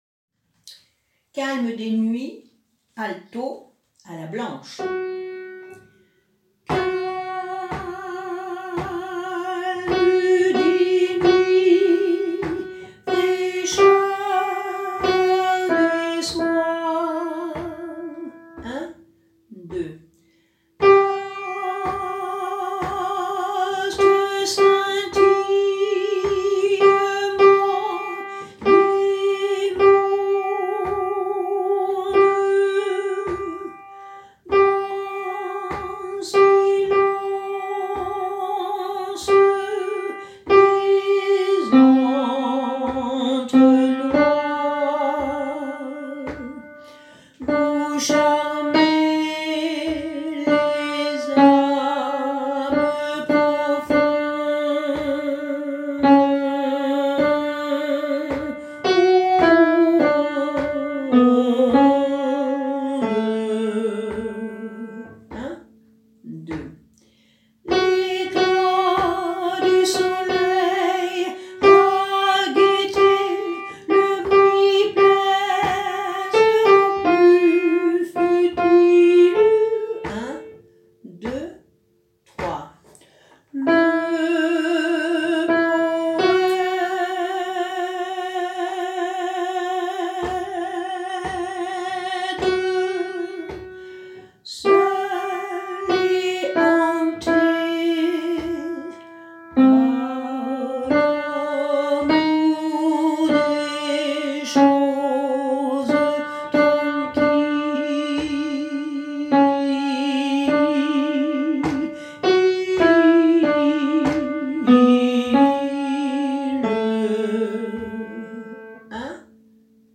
Calme des nuits alto a la blanche